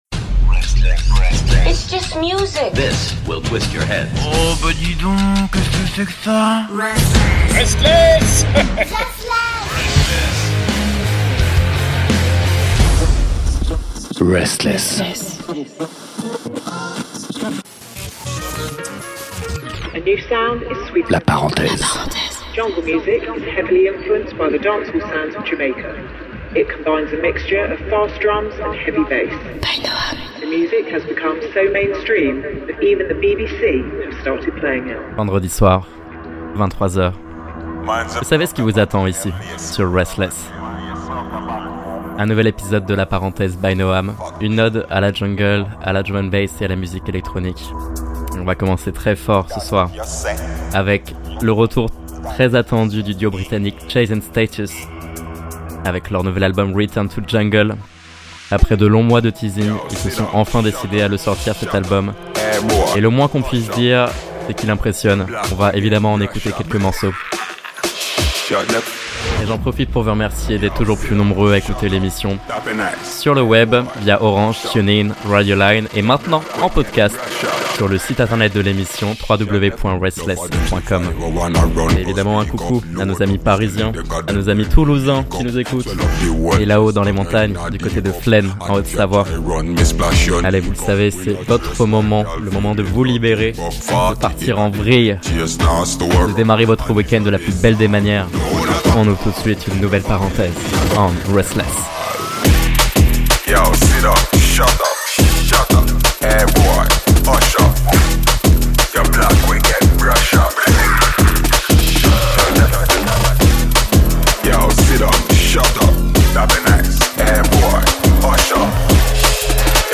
Drum&Bass